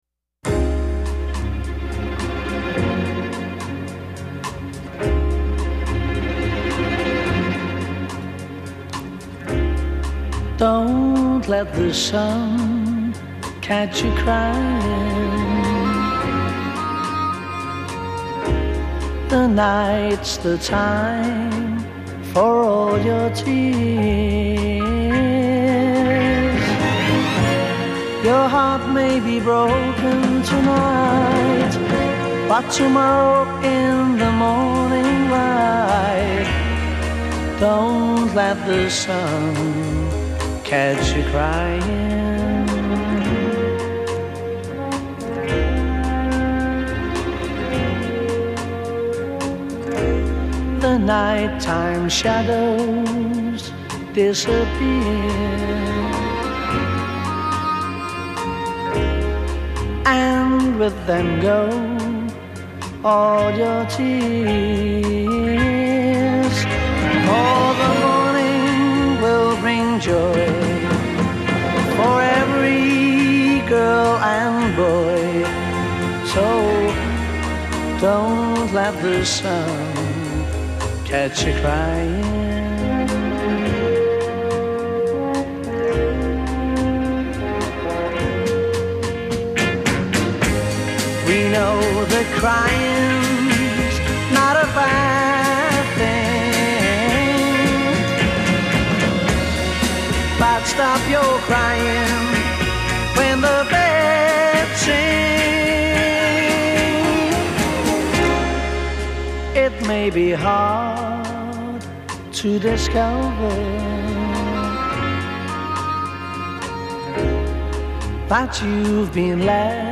Recorded 9 December 1963 at EMI Recording Studios, London
intro 0:00 4 orchestra with string tremolo
B chorus : 8 second drum track doubles first c
A verse : 16 drop second drum track d